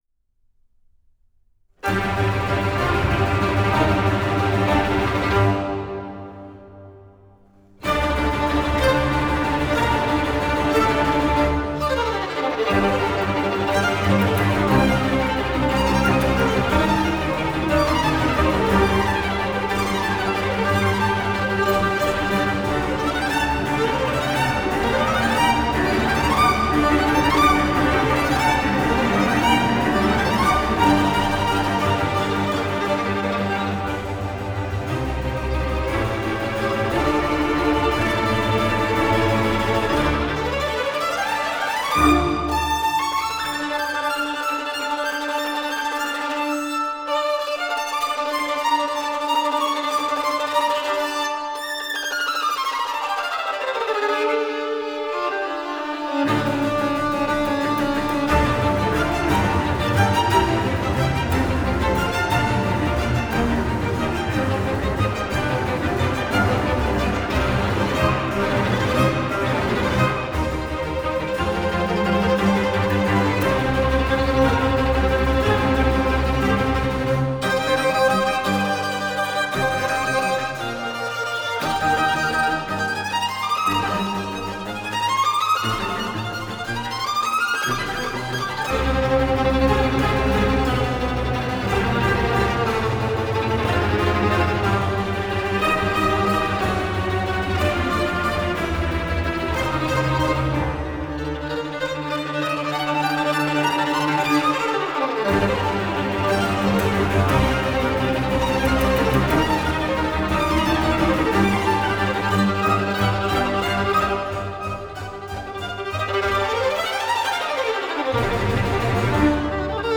Genre: Classical, Violin